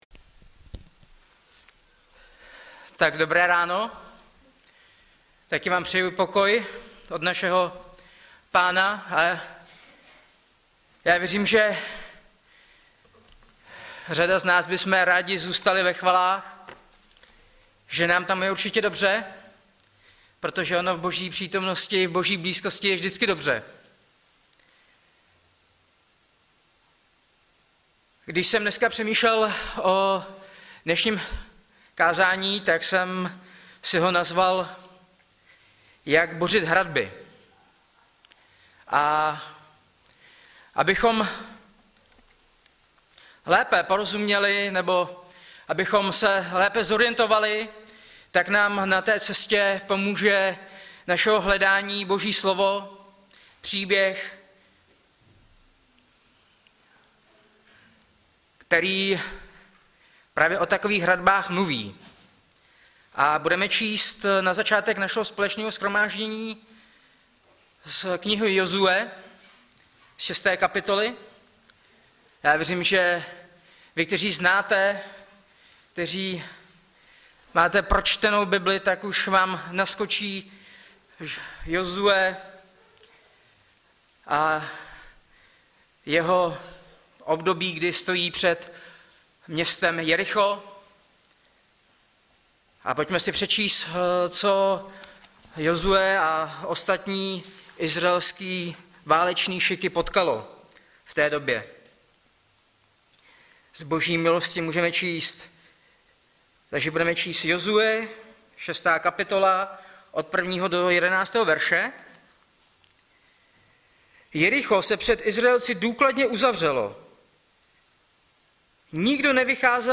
Webové stránky Sboru Bratrské jednoty v Litoměřicích.
Audiozáznam kázání